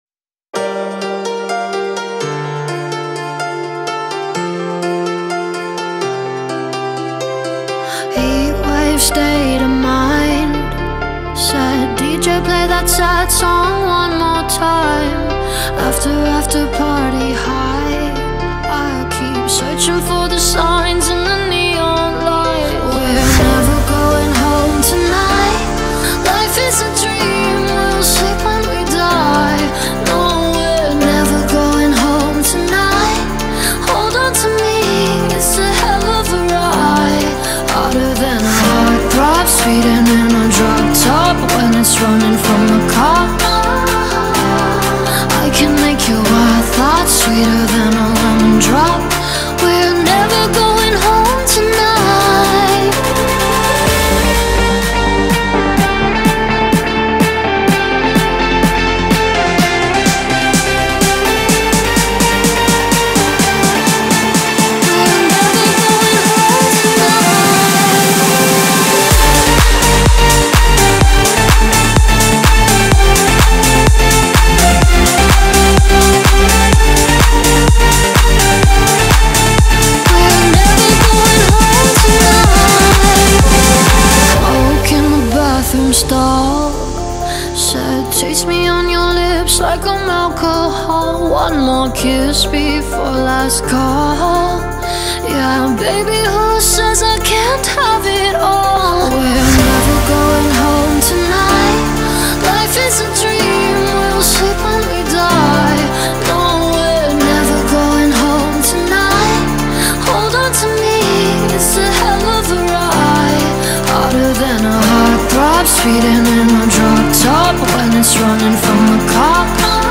Produção: Gostaria que esse anúncio se repetisse duas vezes durante a música que enviei em anexo (trilha). Gostaria que coloca o som de moeda e notificação do whatsapp.